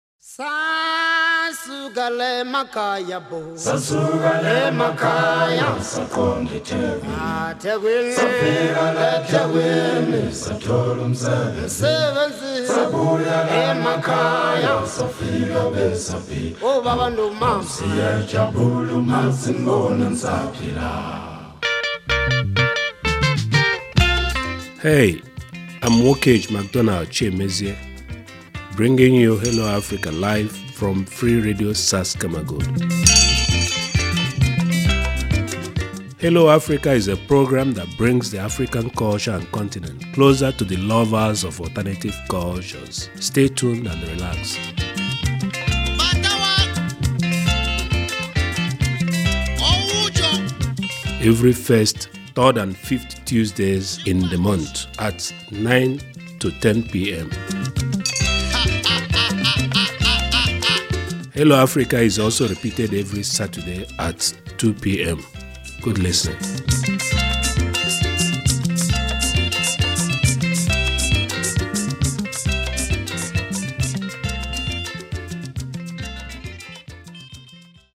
Sendungstrailer
FRS-TRAILER-HELLO-AFRICA_neu.mp3